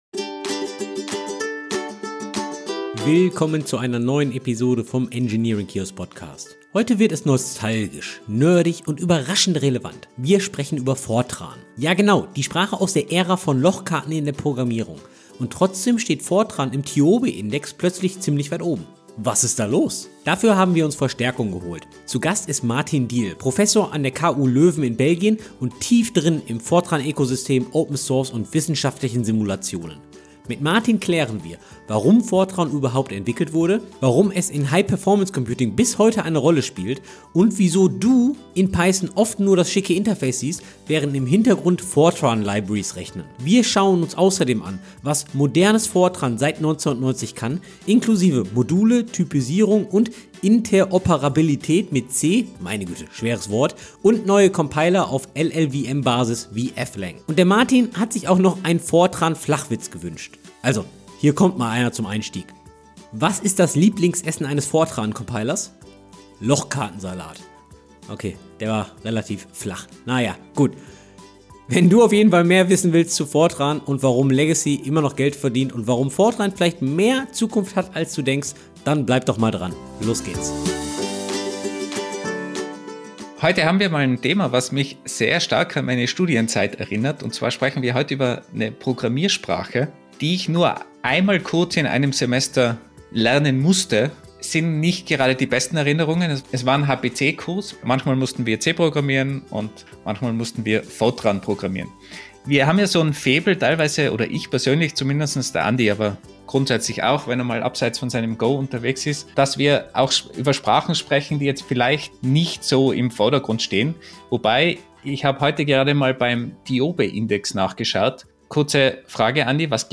In dieser Interviewfolge nehmen wir Fortran auseinander, aber fair.